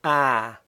ɑ-01-PR-open_front_unrounded_vowel.ogg.mp3